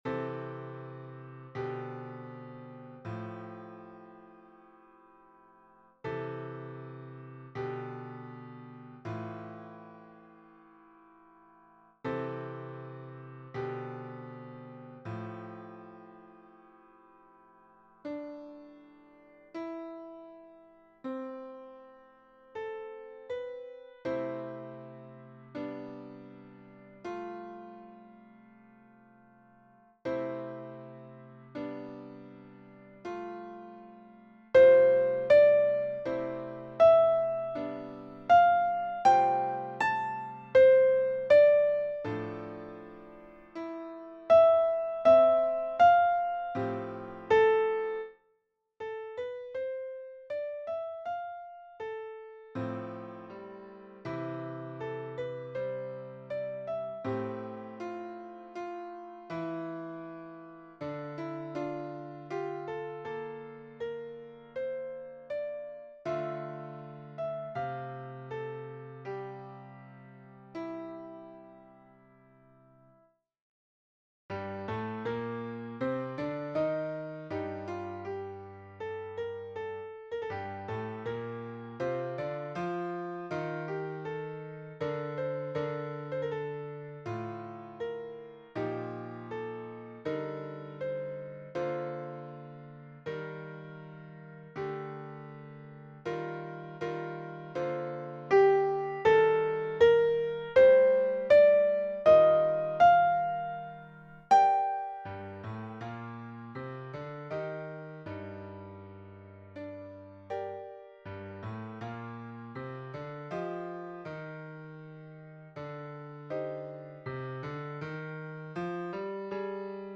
- Pièce pour chœur à 4 voix mixtes (SATB) + piano